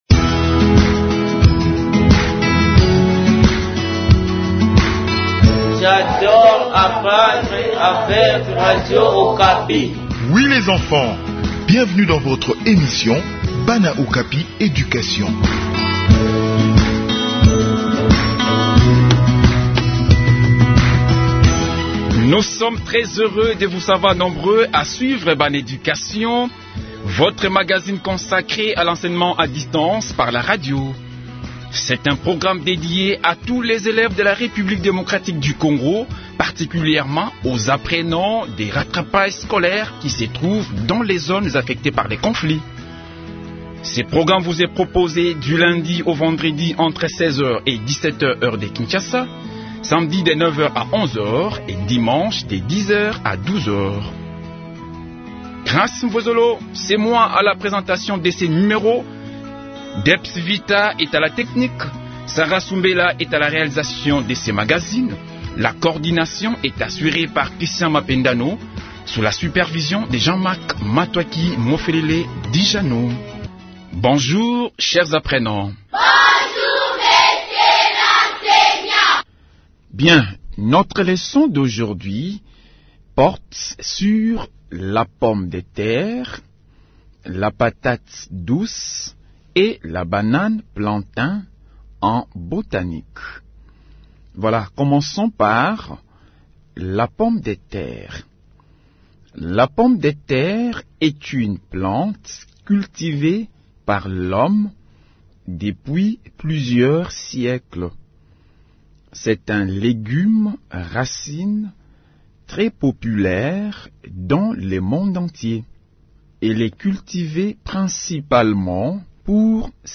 Enseignement à distance : leçon de botanique sur la pomme de terre, la banane plantain et la patate douce | Radio Okapi